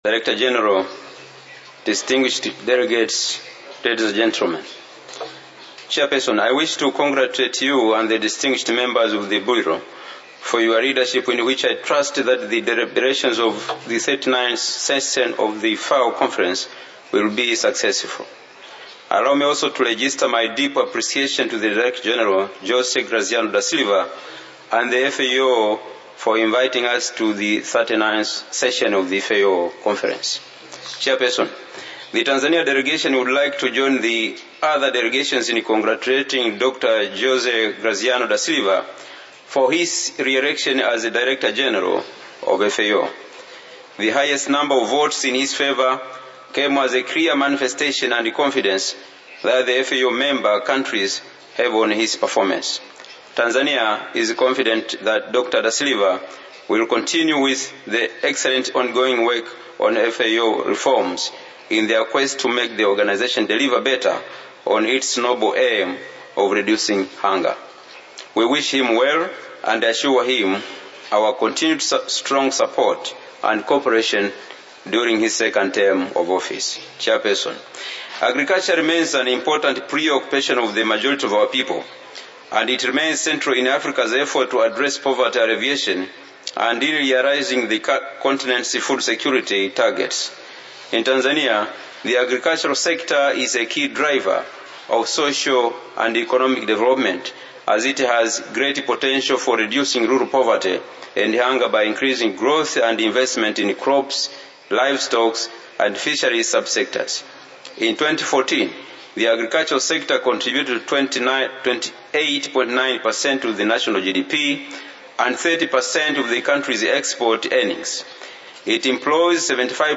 FAO Conference
Statements by Heads of Delegations under Item 10:
Mr Godfrey Weston Zambi, Deputy Minister for Agriculture, Food Security and Cooperatives of the United Republic of Tanzania